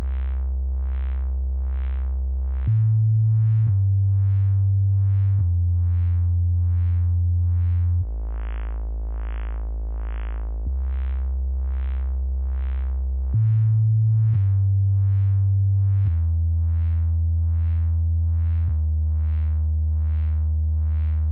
bass.mp3